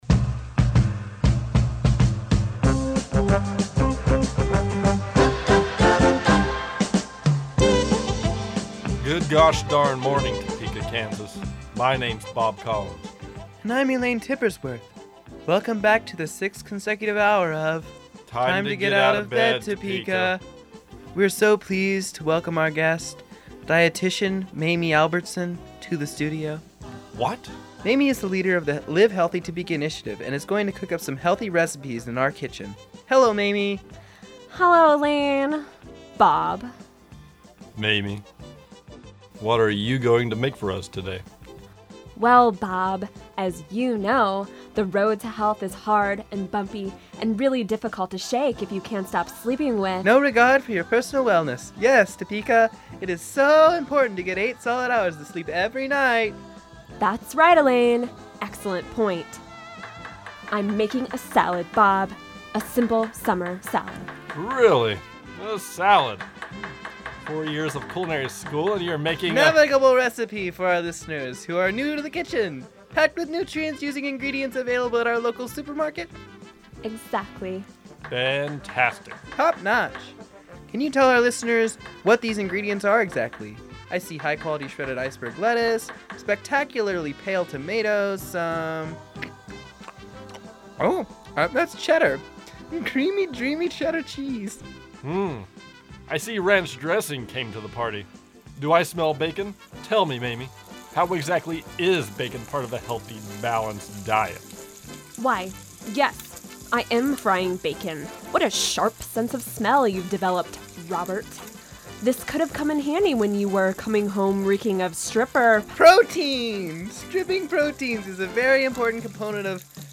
Episode 2 of our sketch comedy program brings you our take on network radio, our live fantasy draft, and the next installment of our sci-fi series.
Tune in to the Dancing Bear Variety Hour the second Tuesday of every month at 7:00pm on KJHK 90.7fm.